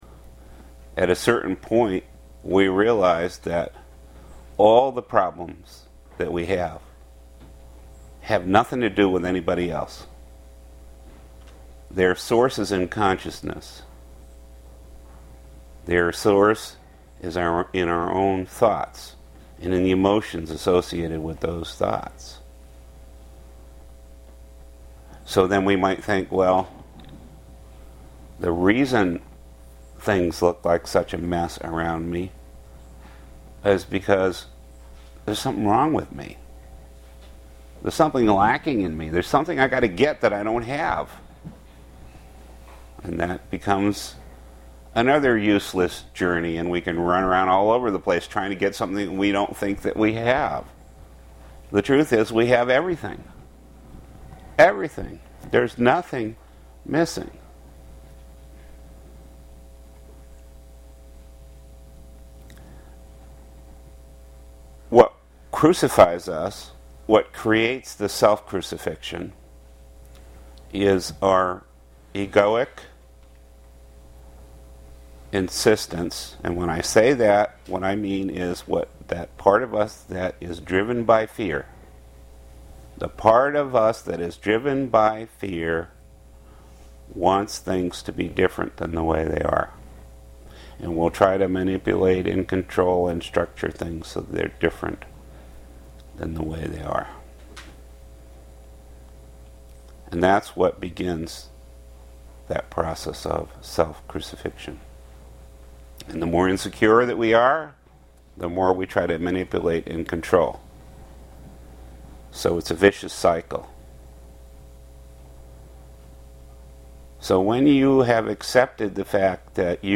Two Talks Given at the Vermont Spiritual Mastery Retreats
A recording from the 2005 Vermont Retreat.